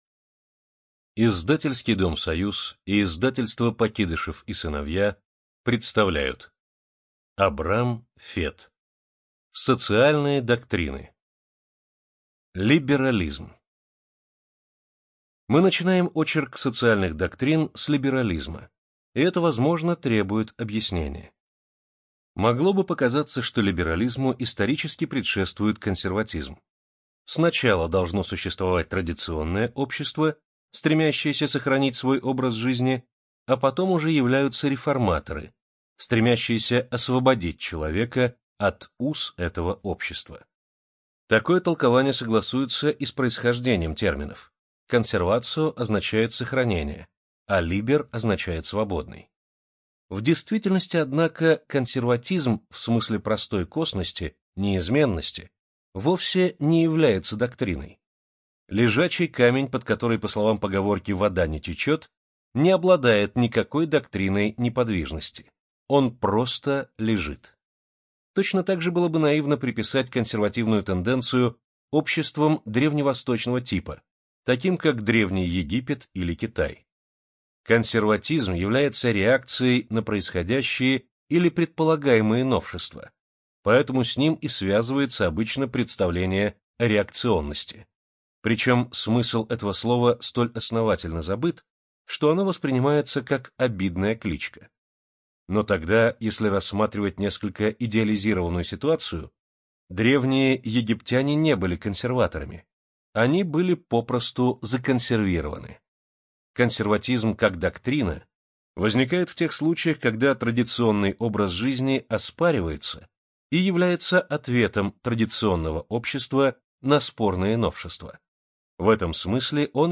Студия звукозаписиСоюз